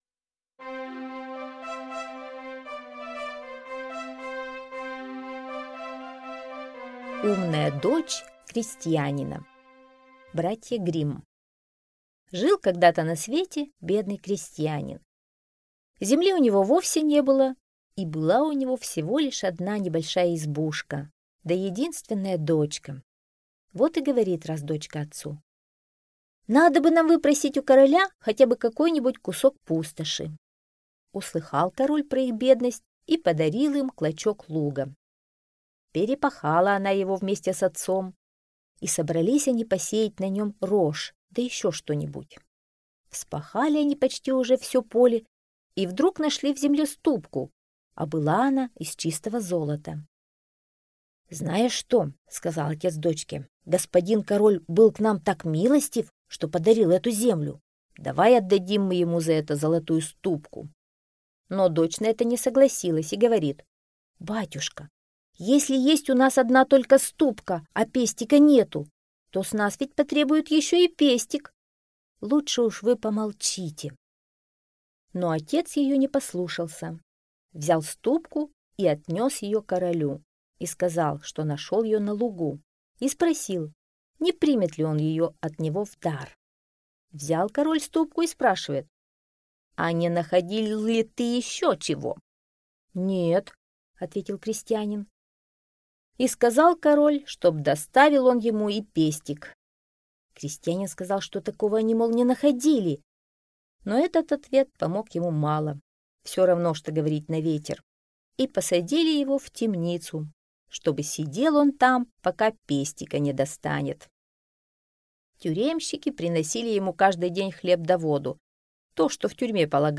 Умная дочь крестьянская - аудиосказка братьев Гримм. Сказка про дочь крестьянина, которая была такая разумная, что король женился на ней.